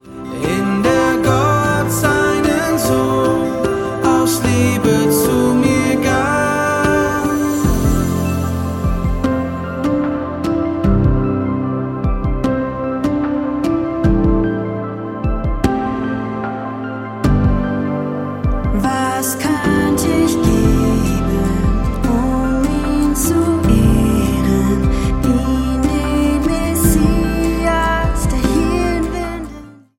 ihr erstes, lang erwartetes Studio-Album